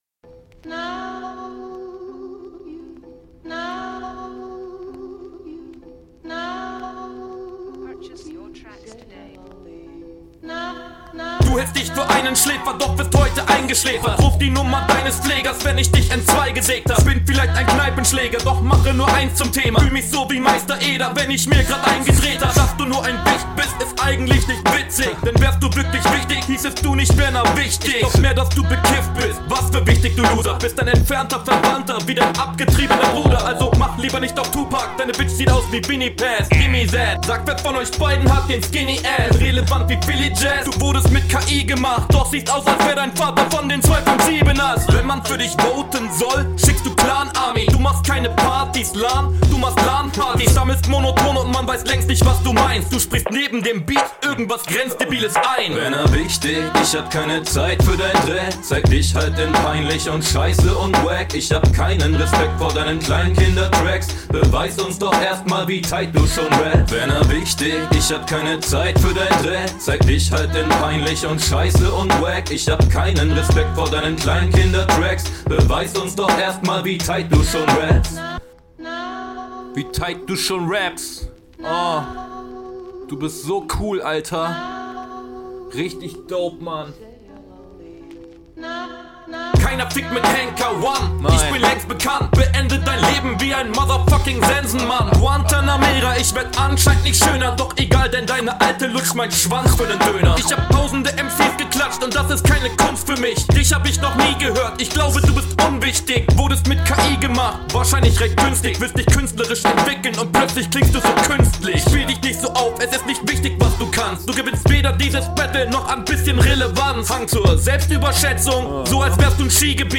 Beat ist komplett geil finde ich.